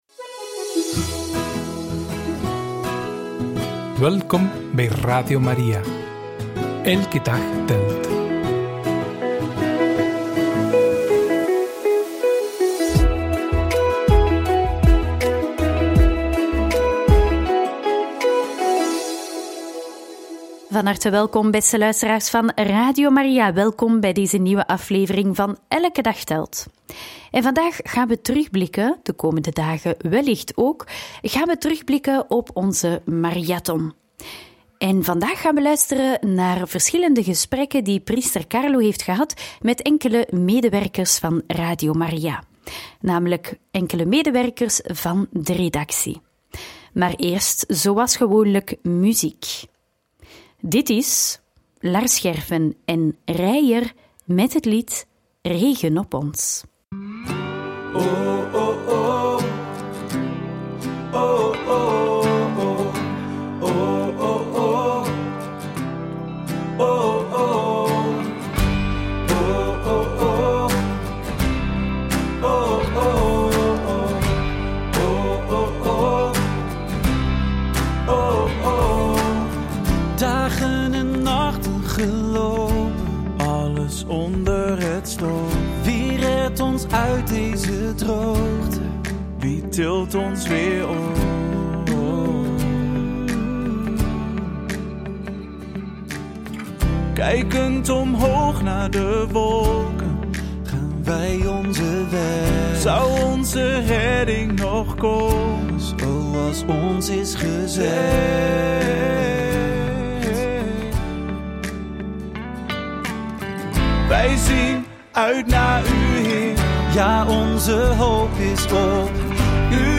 in gesprek met enkele leden van de redactie